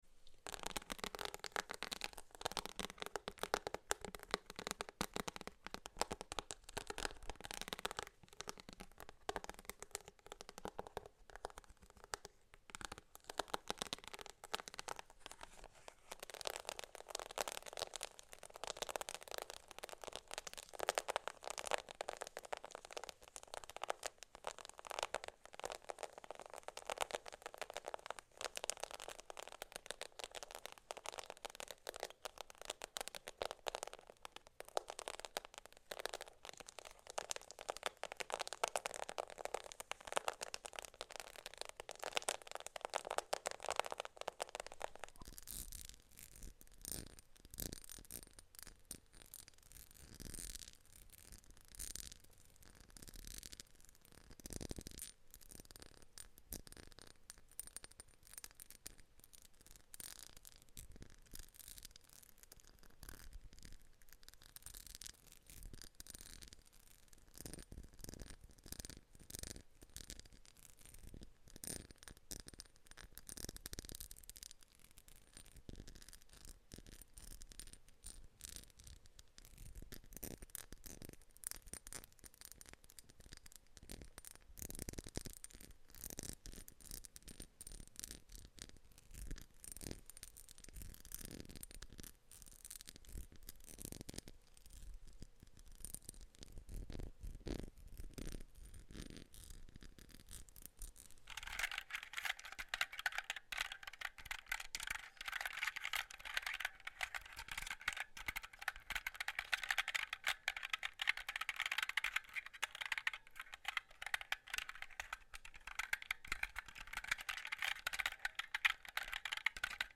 I Love A Soothing, Textured Sound Effects Free Download